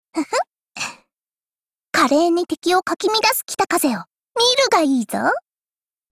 Cv-39903_battlewarcry_3.mp3 （MP3音频文件，总共长6.1秒，码率320 kbps，文件大小：239 KB）
贡献 ） 协议：Copyright，人物： 碧蓝航线:北风语音 您不可以覆盖此文件。